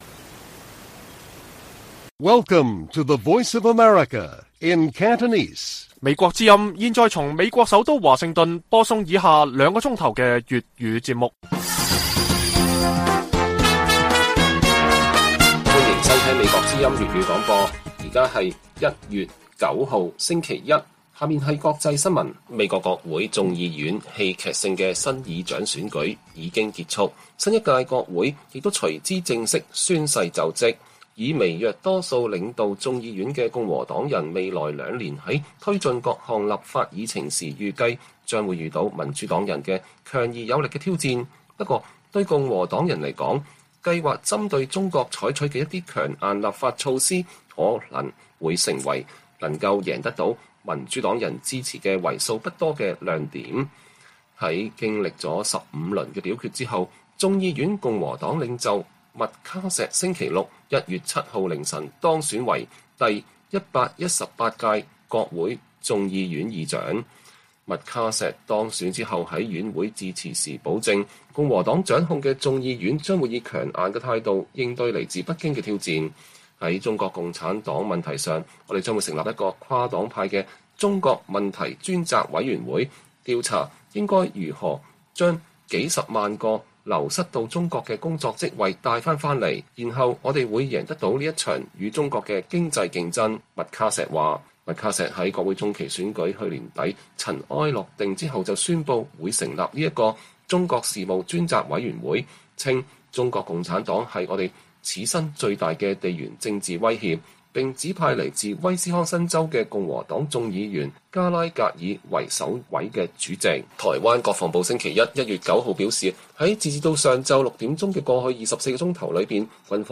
粵語新聞 晚上9-10點: 美國國會新議長將重點推進對北京強硬立法措施